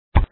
124_Drop_SoundBar_DROP_CLASS.mp3